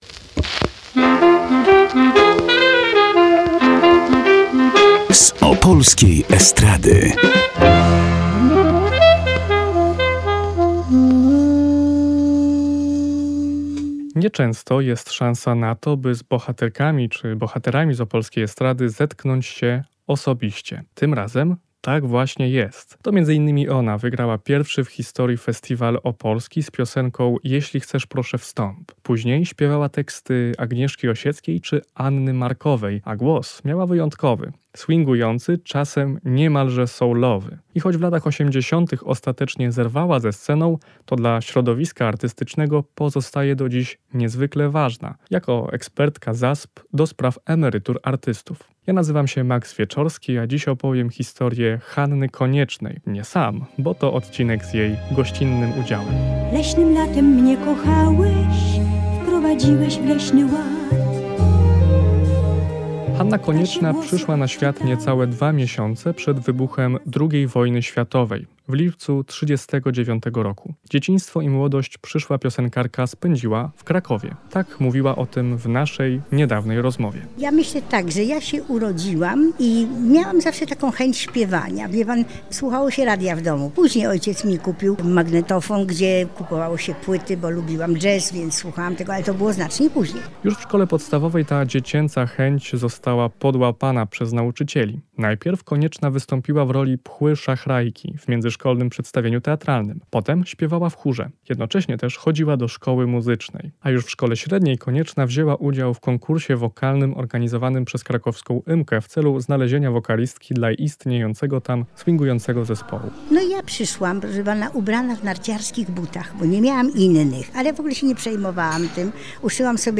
A głos miała wyjątkowy – swingujący, czasem niemalże soulowy.
To odcinek o Hannie Koniecznej, która gościnnie sama w nim występuje.